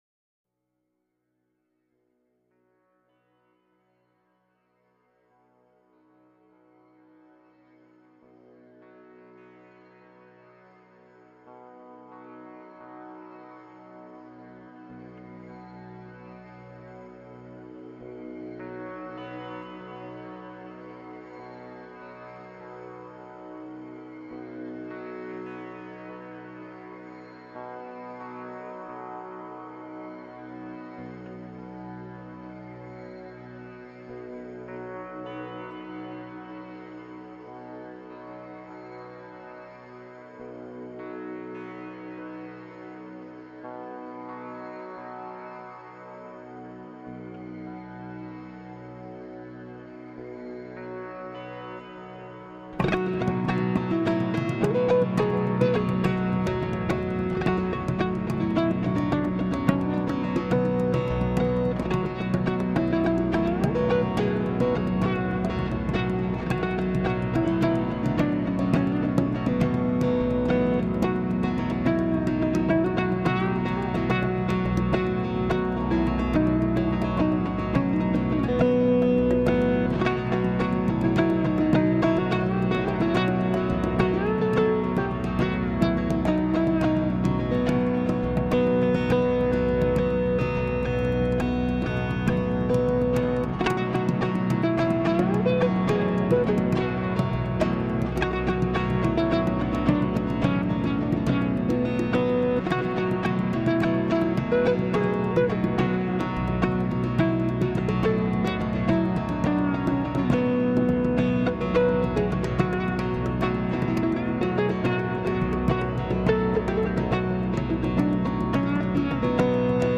系列名称：健康养生音乐 西方冥想音乐 心灵音乐系列（一）